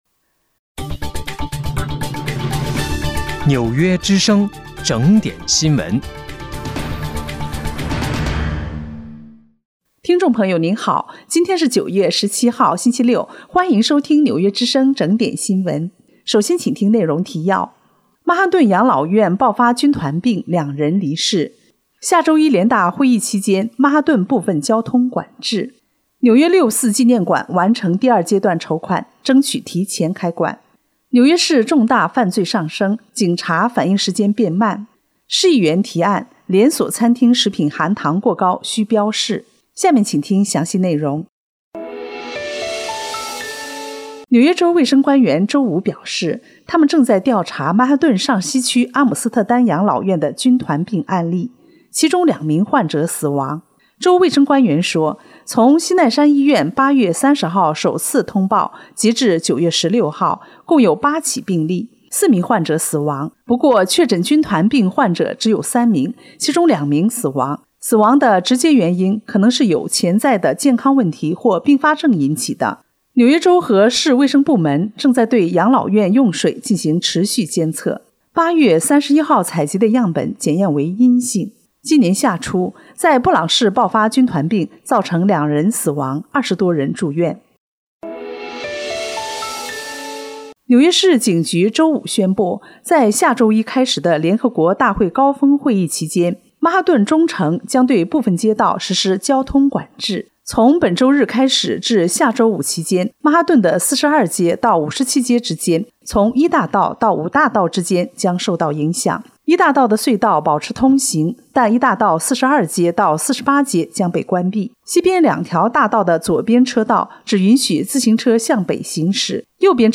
9月17号（星期六）纽约整点新闻